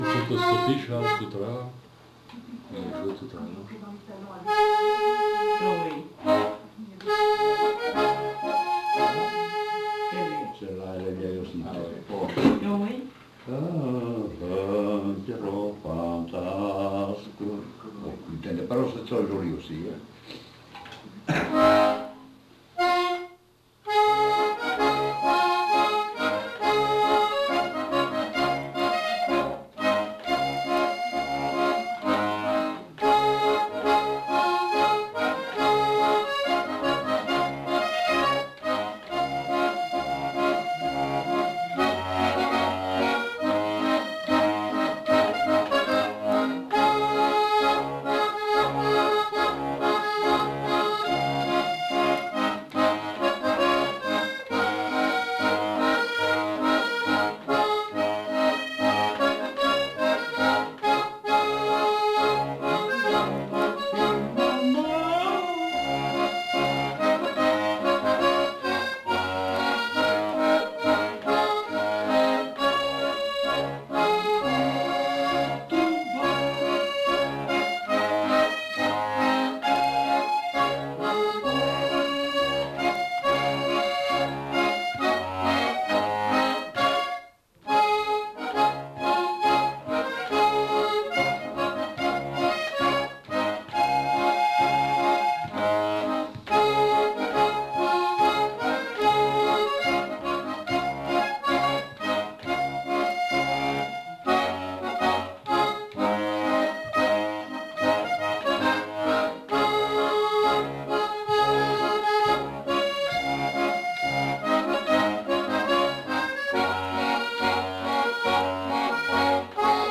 Aire culturelle : Bigorre
Lieu : Aulon
Genre : morceau instrumental
Instrument de musique : accordéon diatonique
Danse : scottish